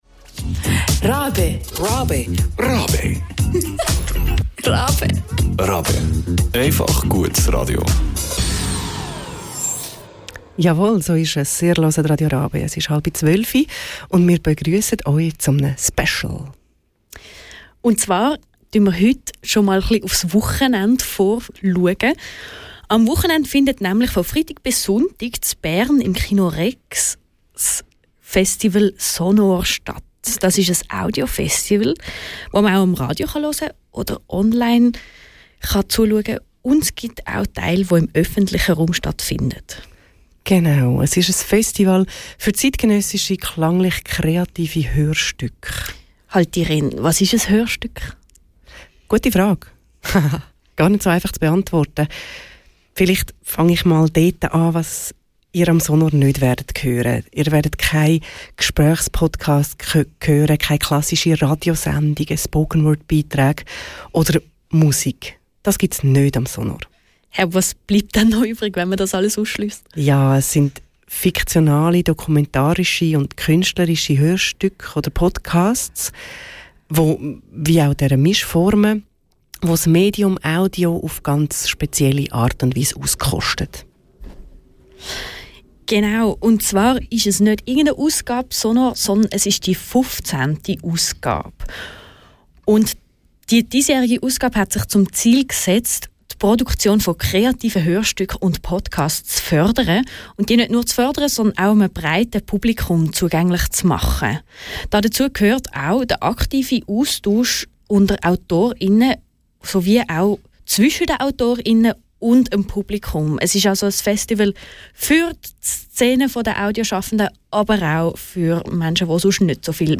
Die 15. Ausgabe vom Radio & Podcast Festival sonOhr steht vor der Tür und es hat viel zu bieten. Was genau, erfahrt ihr in dieser stündigen Spezialsendung.